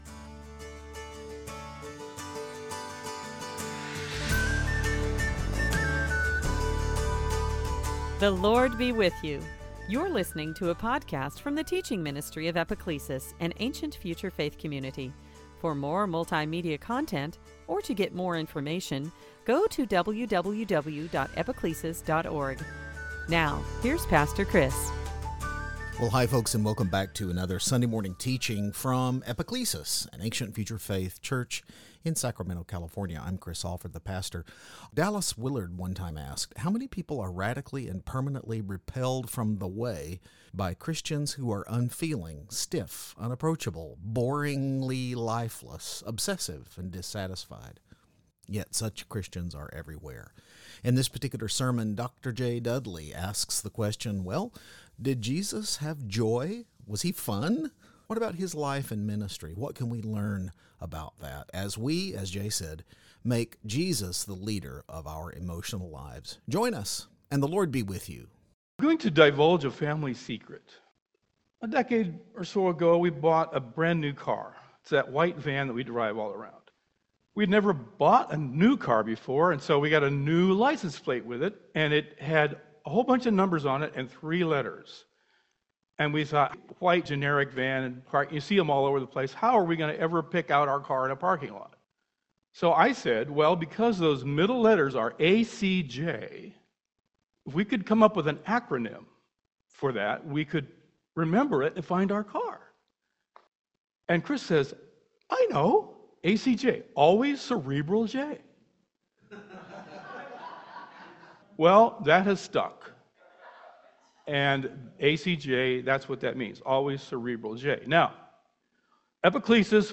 This sermon explores the spectrum of simple to profound enjoyments Jesus knew in His life and ministry, and suggests that Jesus is not the problem, but the solution to the problem as we make Him the leader of our emo